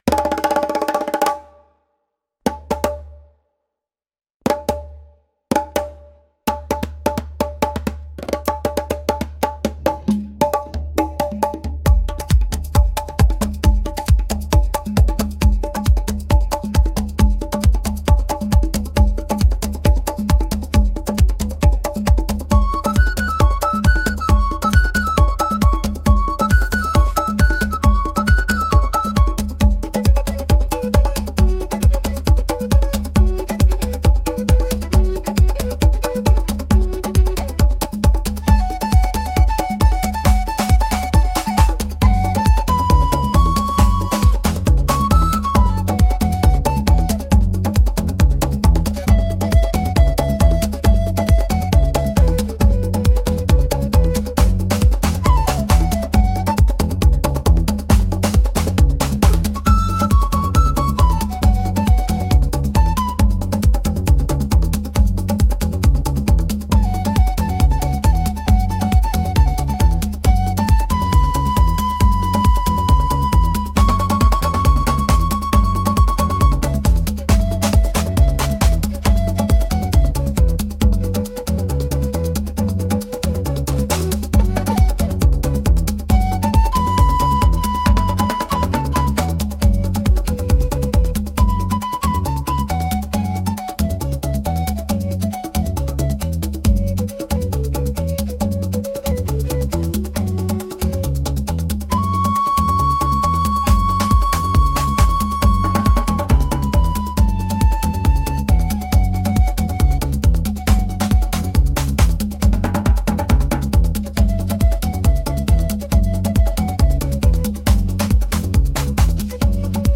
アフリカの民族音楽風
アフリカの民族音楽のような、ジャンベやコンガを使った楽曲を生成してみます。
民族音楽
Instrumental only, No vocals, Energetic African-inspired world music with traditional percussion such as djembe and congas, rhythmic chanting, wooden flutes, and marimbas, Complex polyrhythms, earthy and organic sound, uplifting and tribal atmosphere
AfricanといえばAfricanですが、和のテイストも感じられる仕上がりとなってしまいました。